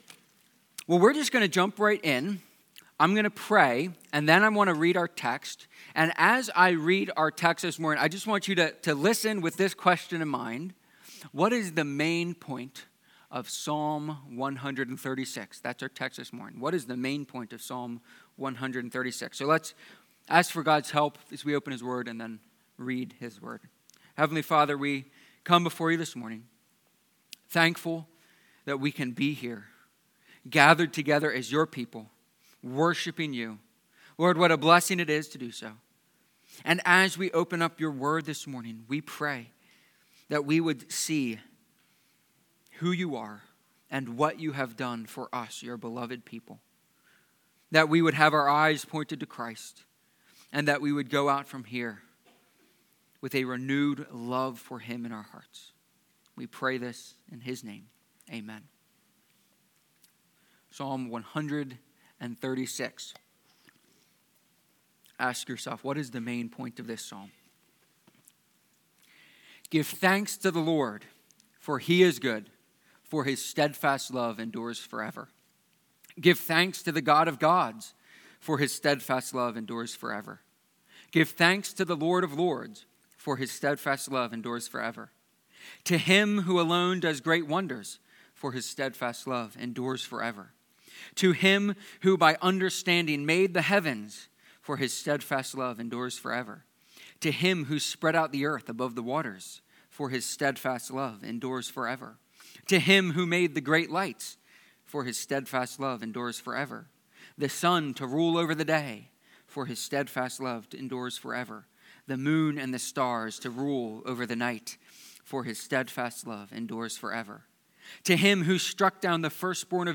Psalm-136-sermon.mp3